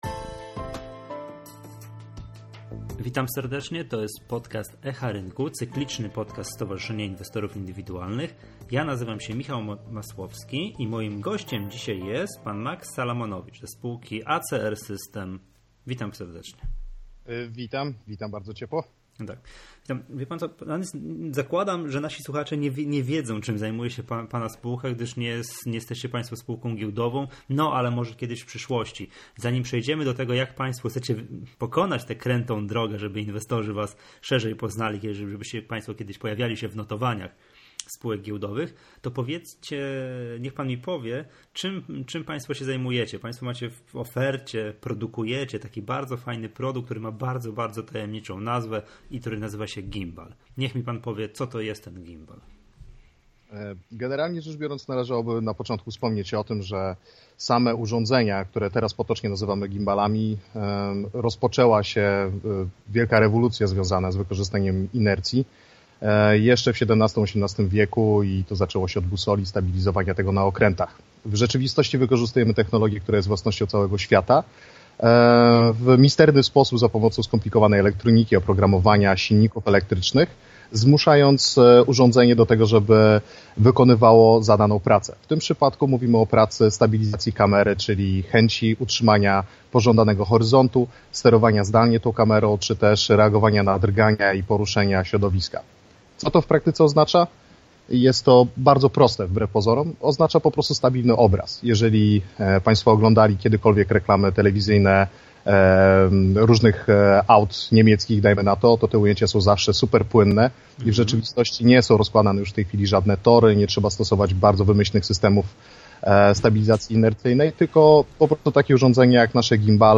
Jak słuchać podcastu Echa Rynku?